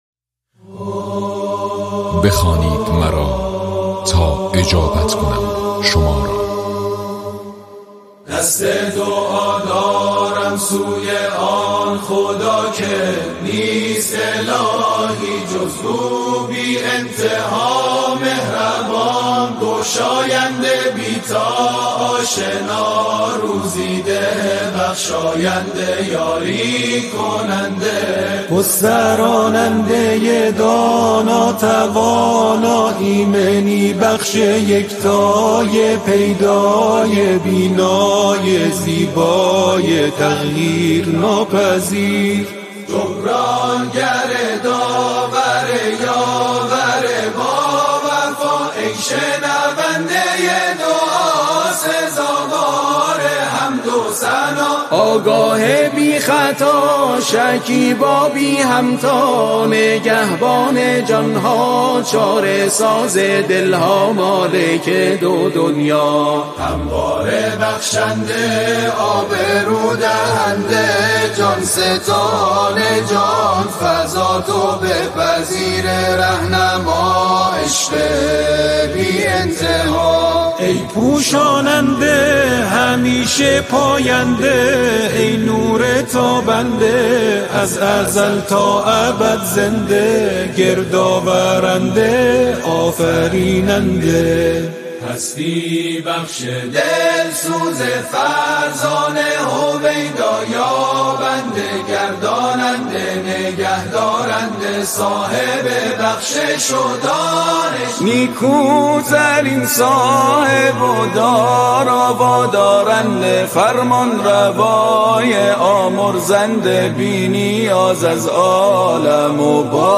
ضبط: استودیو کوثر